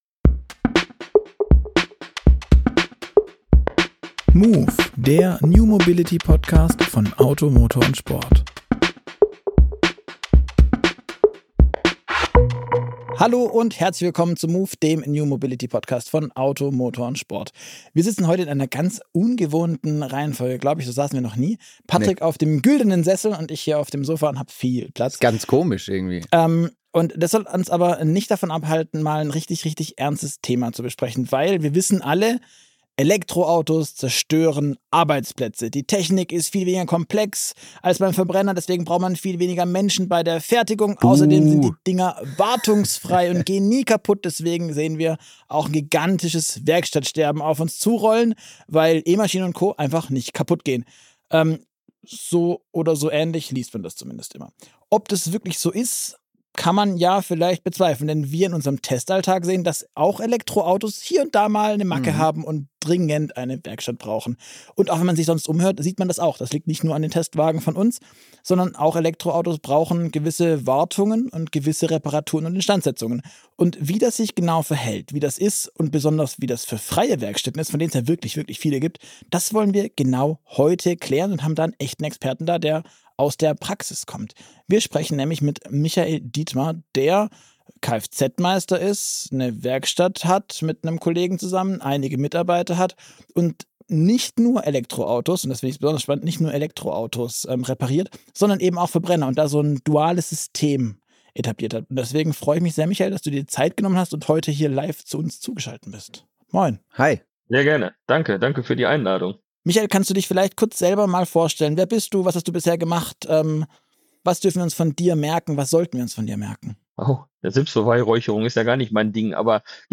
Ein ehrliches Gespräch über Herausforderungen, Chancen und den Werkstattalltag zwischen Verbrenner und Elektro.